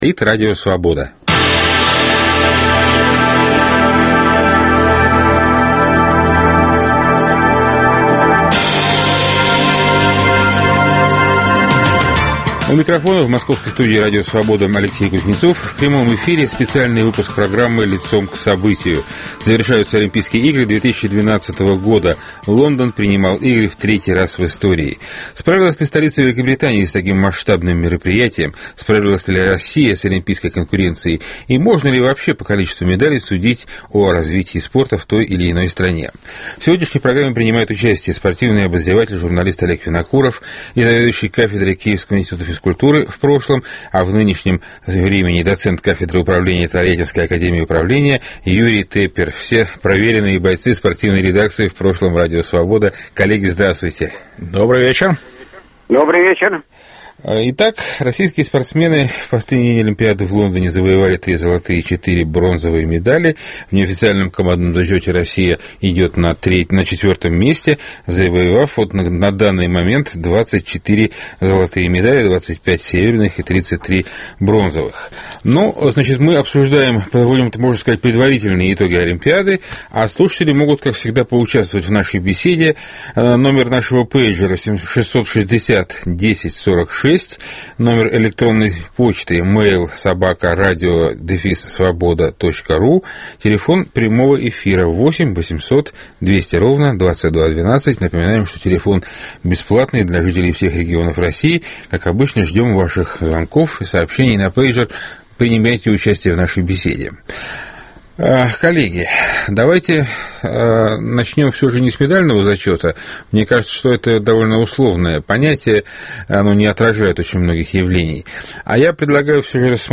В прямом эфире на волнах Радио Свобода – специальный выпуск программы "Лицом к событию".